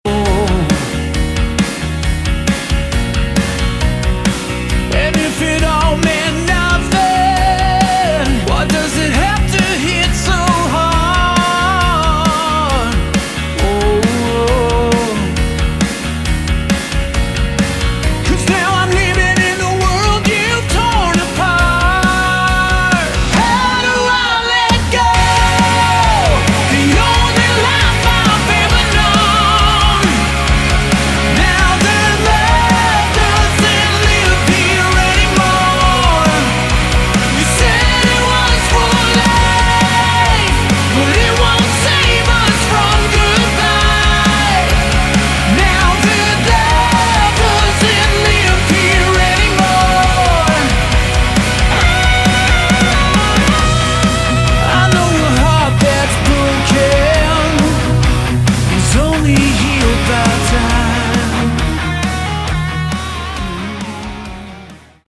Category: AOR / Melodic Rock
bass
vocals, guitar
drums